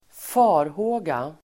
Uttal: [²f'a:rhå:ga]